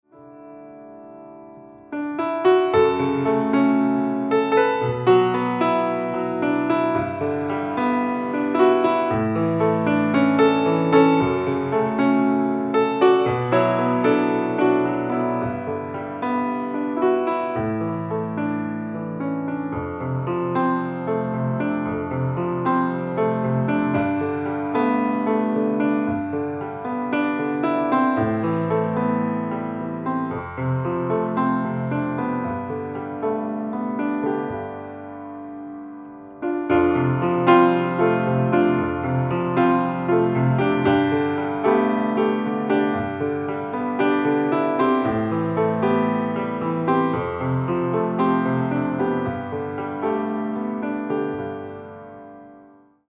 Piano solo "smooth".